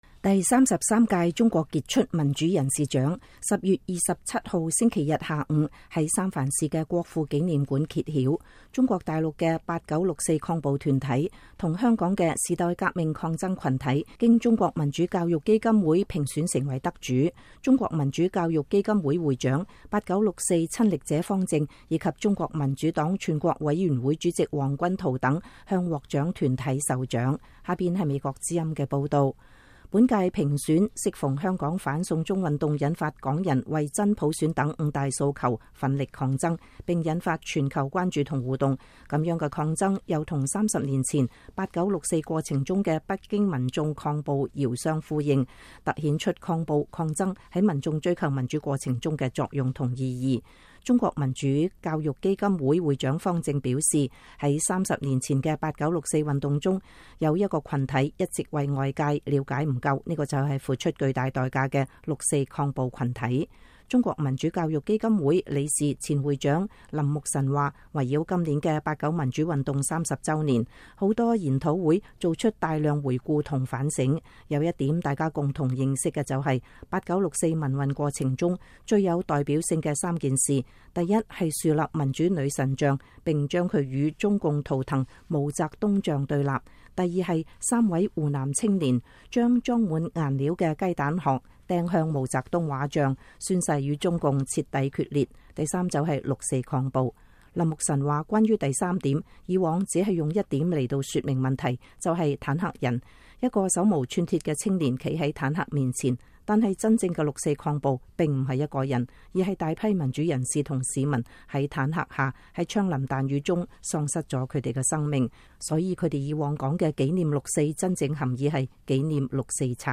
第33屆“中國傑出民主人士”獎10月27日星期天下午在舊金山的國父紀念館揭曉。